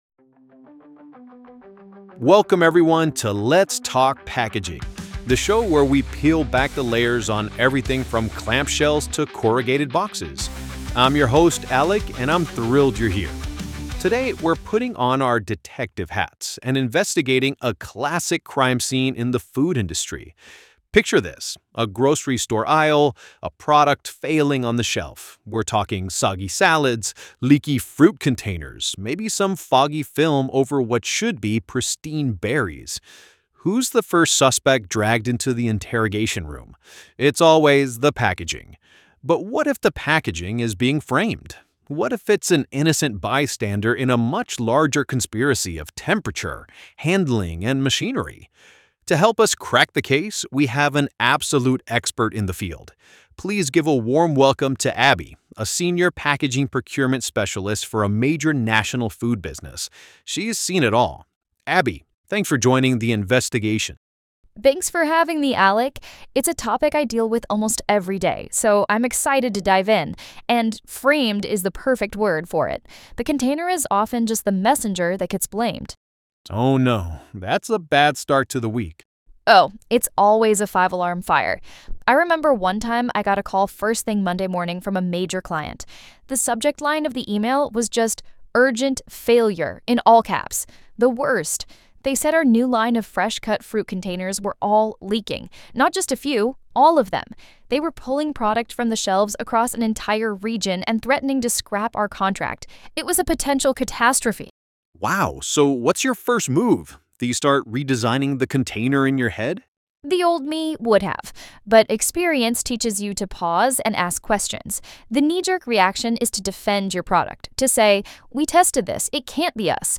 This audio uses AI-generated content and media.